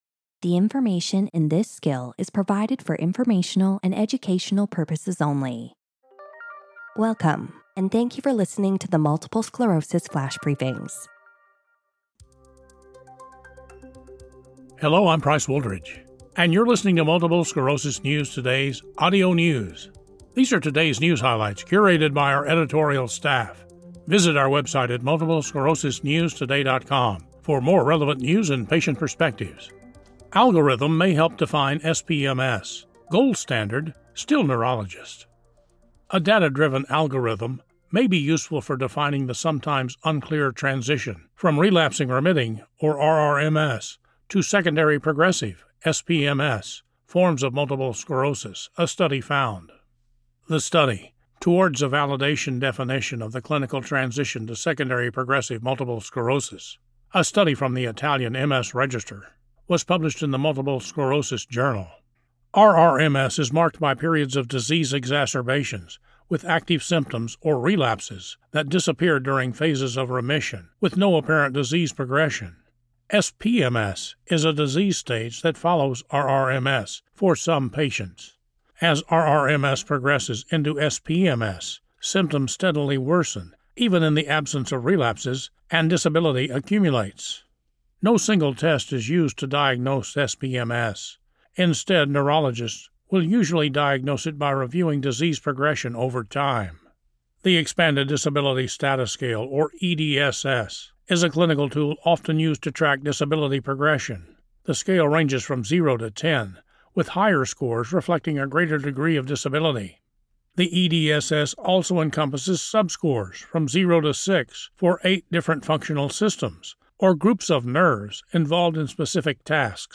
reads a news article about a data-driven algorithm that may help predict when patients convert to secondary progressive multiple sclerosis from a relapsing form of the disease.